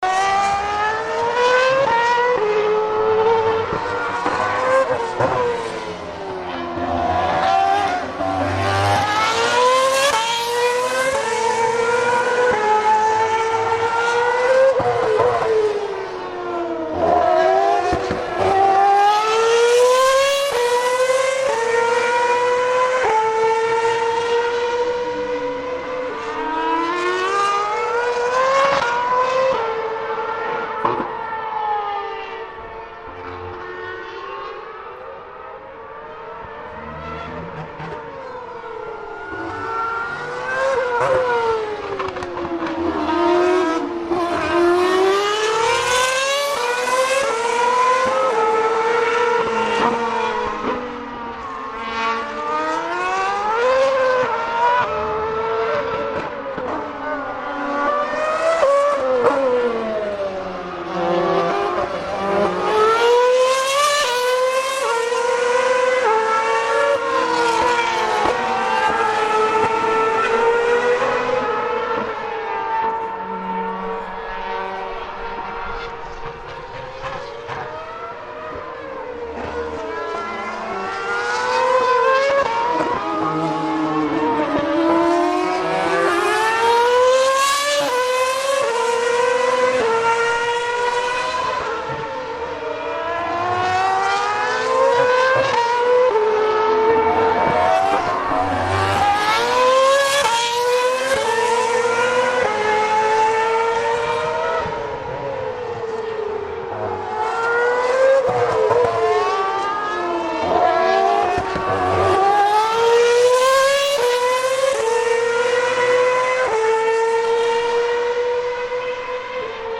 F1 Practice day Barcelona